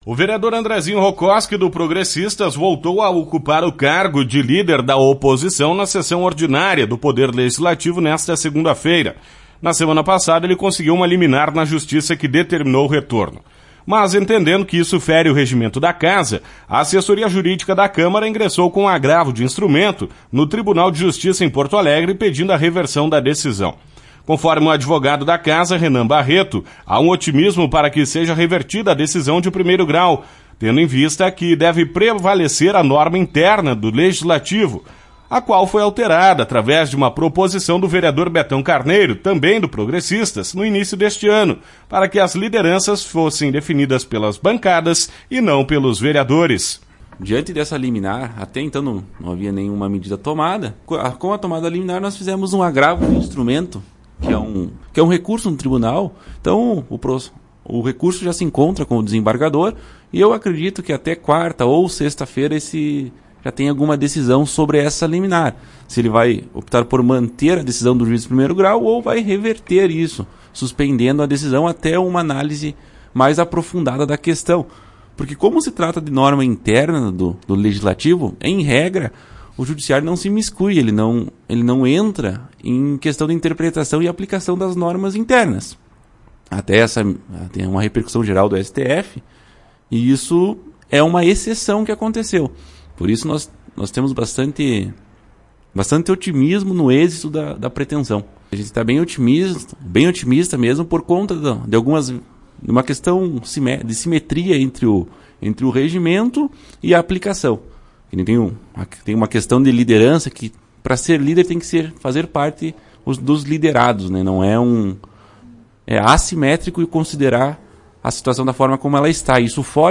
Presidente Fabiano Vacaria (PTB) esteve nos estúdios da Tua Rádio para falar sobre o tema